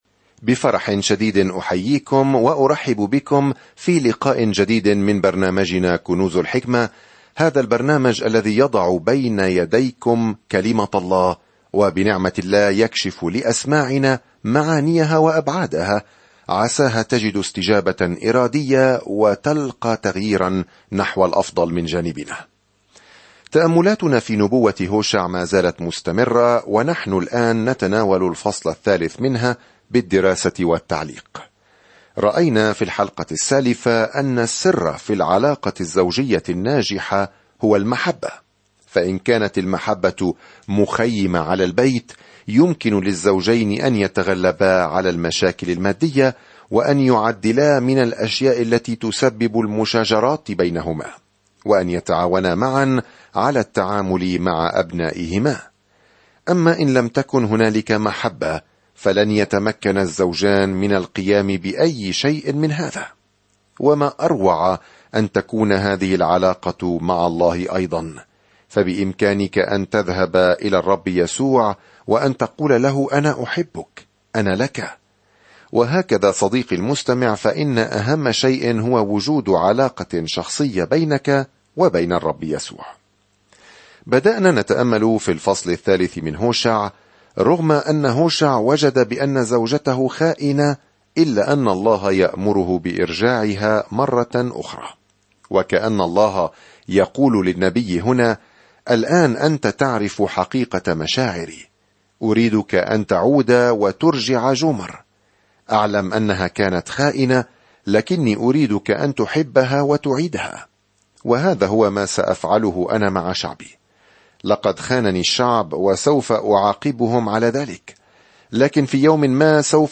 الكلمة هُوشَع 4:3-5 يوم 5 ابدأ هذه الخطة يوم 7 عن هذه الخطة استخدم الله زواج هوشع المؤلم كمثال لما يشعر به عندما يكون شعبه غير مخلصين له، ومع ذلك فهو يلتزم بأن يظل يحبهم. سافر يوميًا عبر هوشع وأنت تستمع إلى الدراسة الصوتية وتقرأ آيات مختارة من كلمة الله.